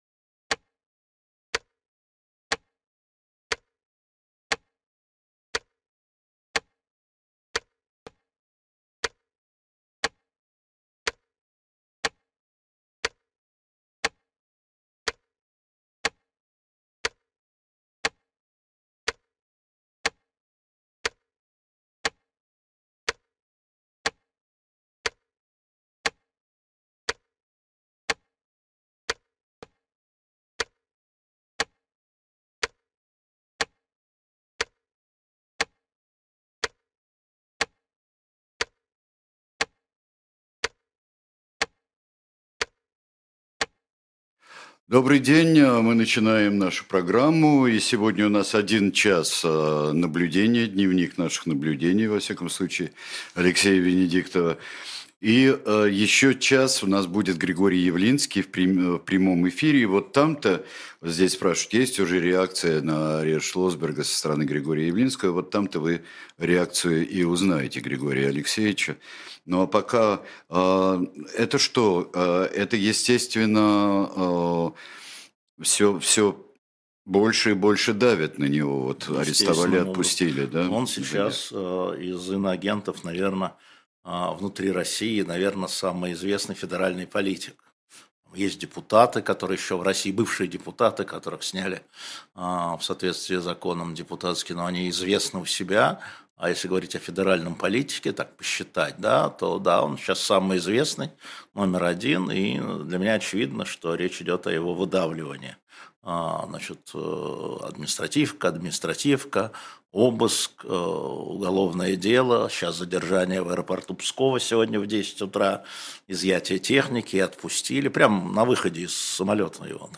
Эфир ведет Сергей Бунтман